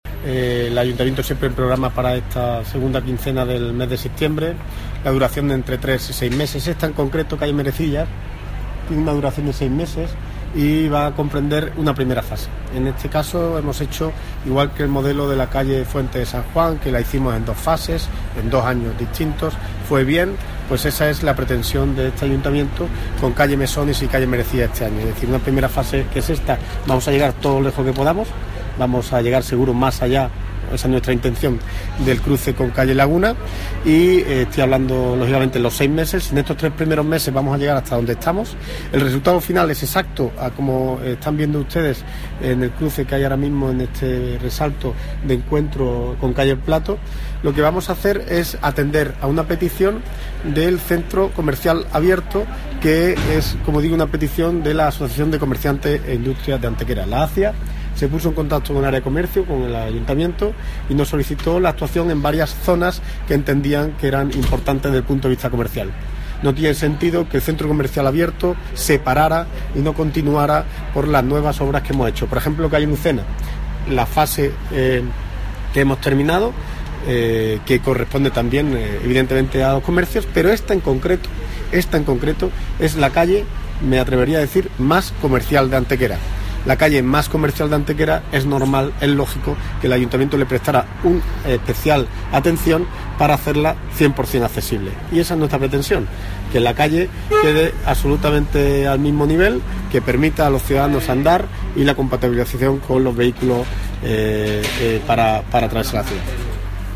El alcalde de Antequera, Manolo Barón, y el teniente de alcalde delegado de Obras y Mantenimiento, José Ramón Carmona, han confirmado hoy en rueda de prensa que ya han comenzado los trabajos relativos a siete de los proyectos incluidos en el PROFEA 2016.
Cortes de voz